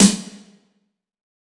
SNARE 072.wav